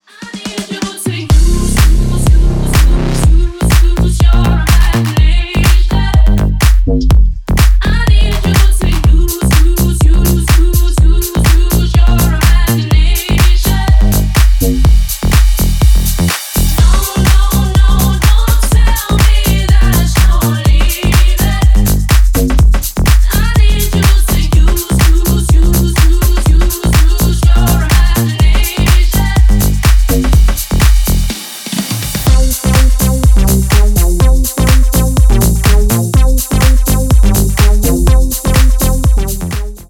Ремикс # Танцевальные